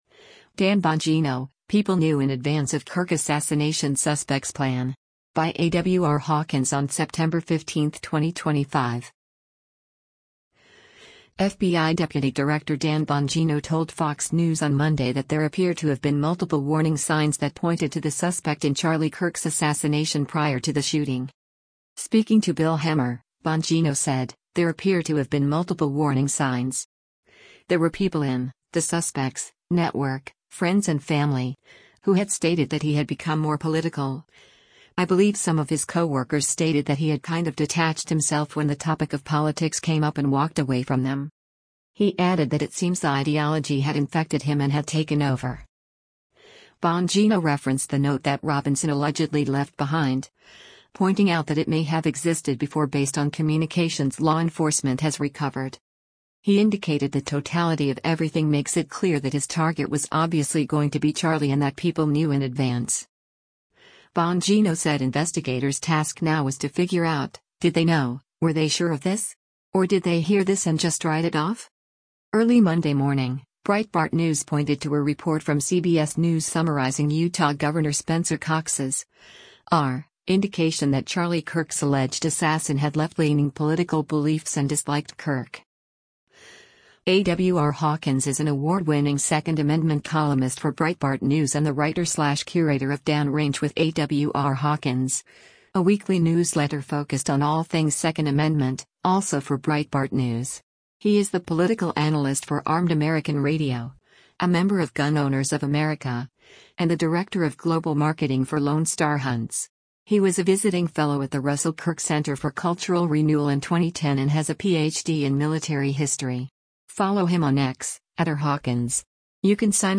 FBI deputy director Dan Bongino told Fox News on Monday that “there appear to have been multiple warning signs” that pointed to the suspect in Charlie Kirk’s assassination prior to the shooting.